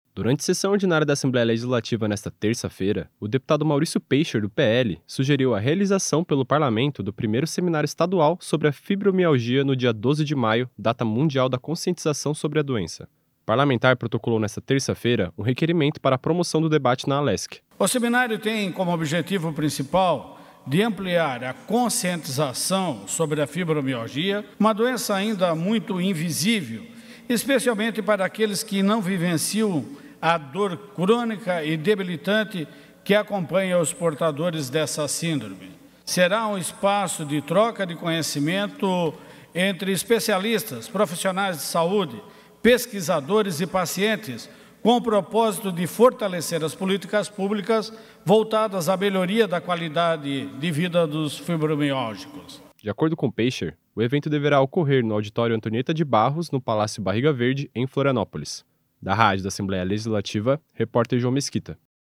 Entrevista com:
- deputado Maurício Peixer (PL), autor do requerimento.